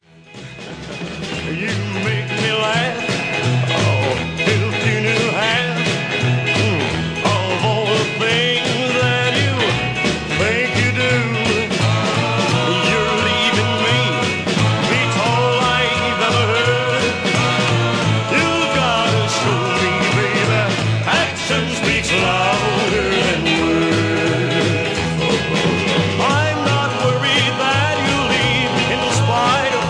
an early obscure 1960\'s elvis style song